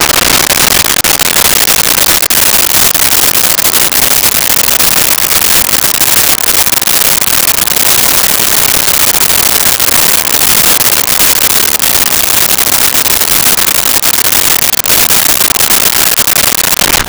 Car On Gravel In Stop
Car On Gravel In Stop.wav